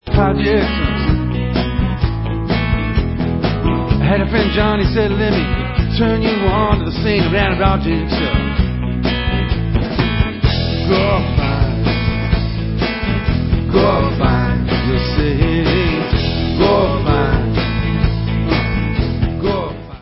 NEW 2005 STUDIO ALBUM